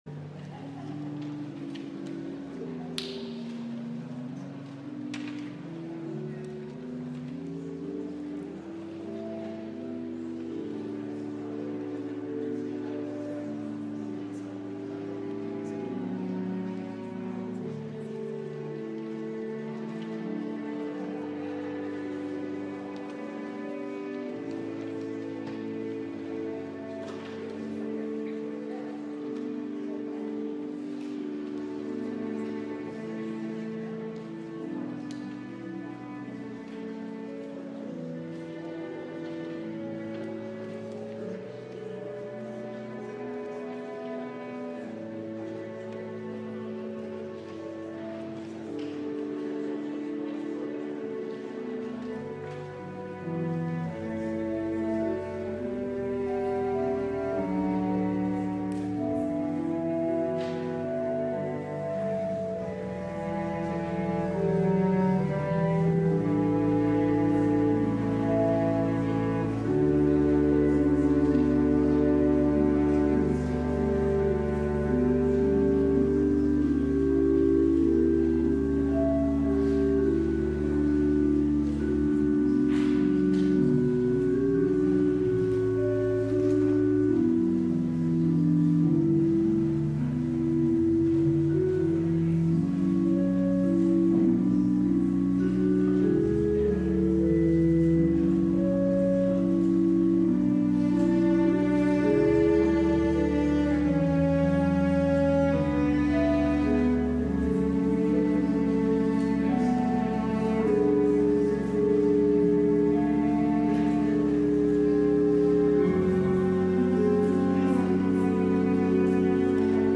LIVE Morning Worship Service - Jesus Promises the Spirit